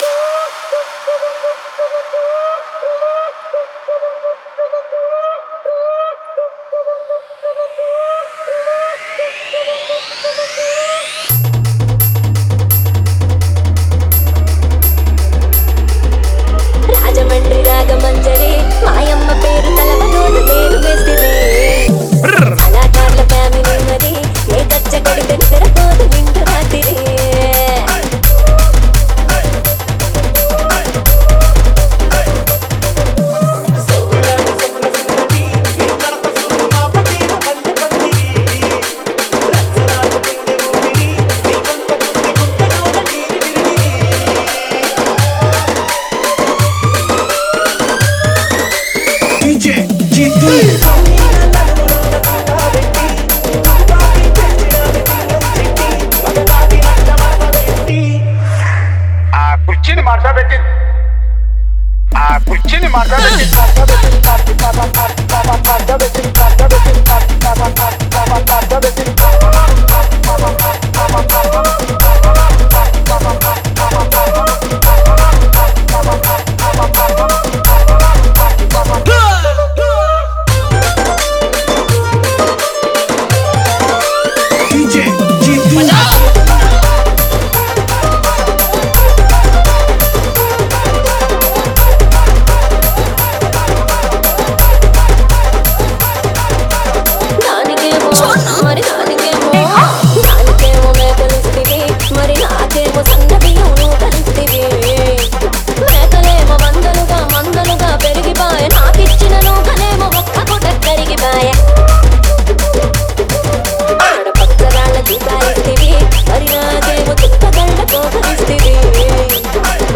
Telug Dj Collection 2024 Songs Download